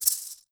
maraca3.wav